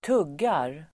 Uttal: [²t'ug:ar]